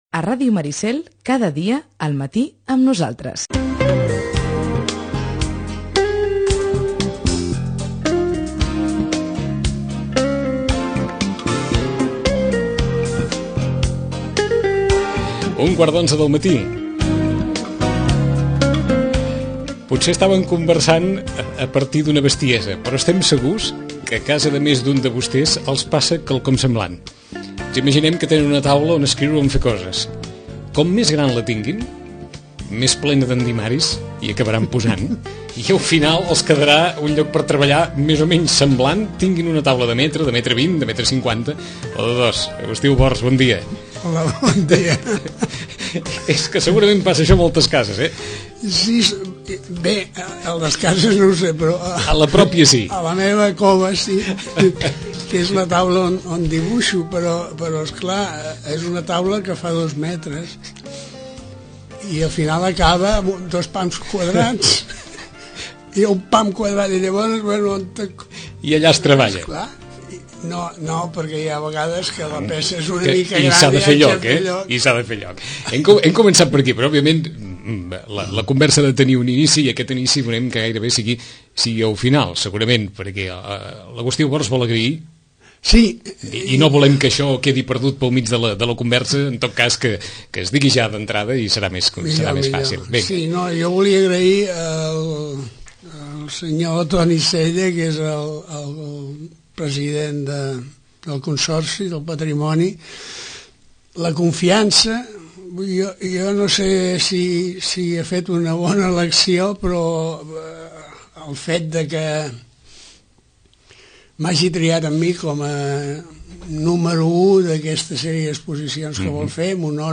Del nostre arxiu sonor recuperem una conversa, que amb motiu d'una exposició antològica l'any 2008, varem mantenir als estudis de la ràdio.